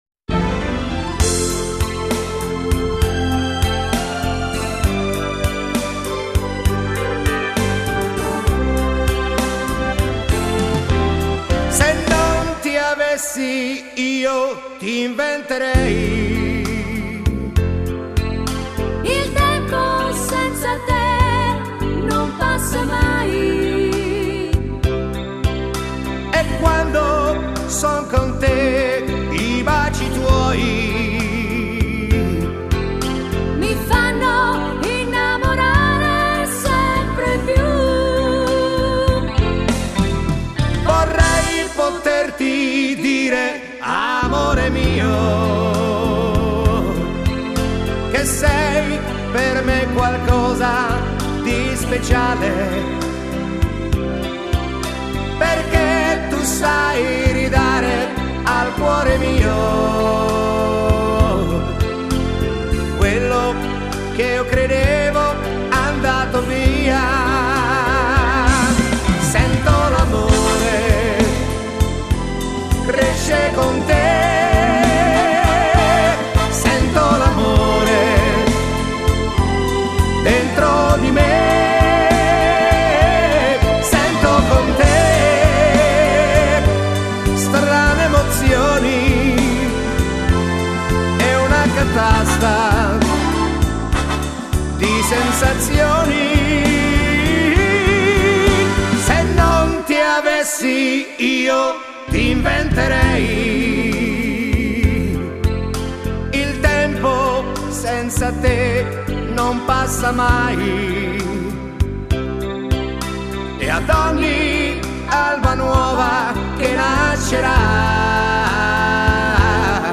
Genere: Lento